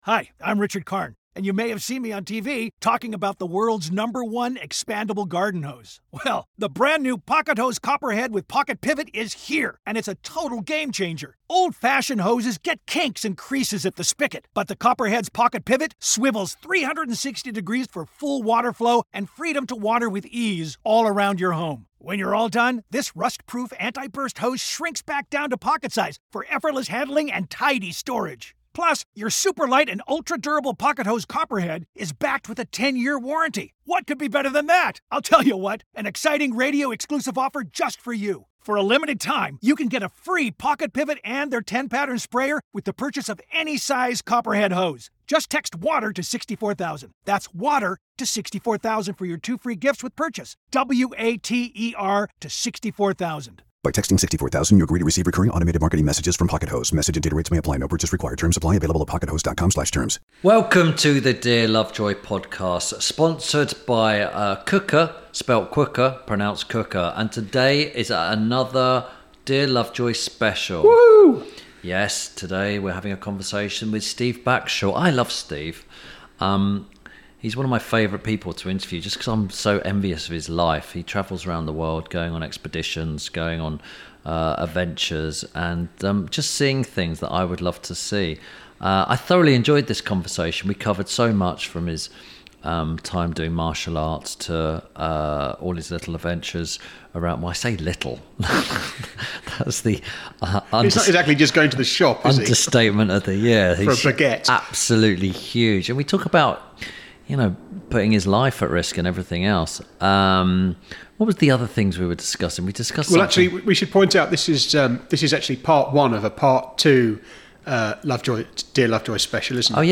– INTERVIEW SPECIAL The first of two interviews with Helen Glover and Steve Backshall. This week Tim Lovejoy talks to naturalist, writer and TV presenter, Steve Backshall. They discuss building their own house, learning and competing judo/martial arts in Japan, being married to a very successful Olympian, how he began in wildlife television, the worst creatures to be bitten by and his groundbreaking new TV show and book, Expedition.